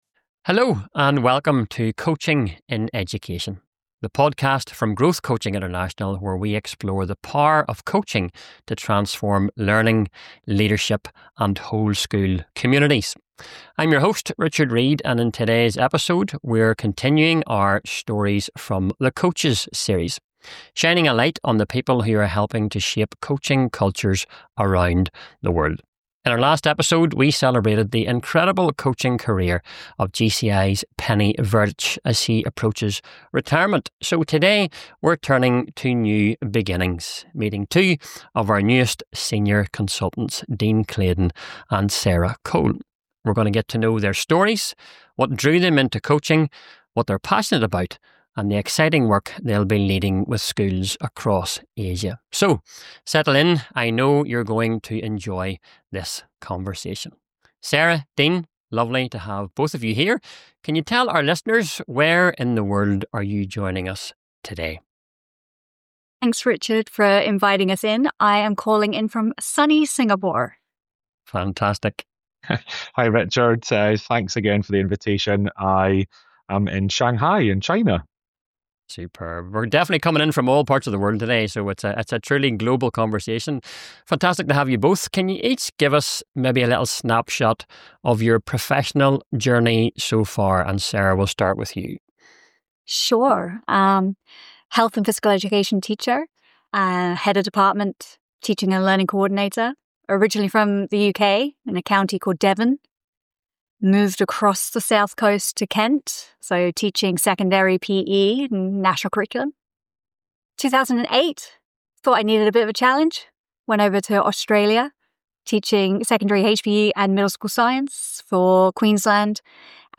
Download In this episode of Coaching in Education, we continue our Stories from the Coaches series with a conversation about new beginnings.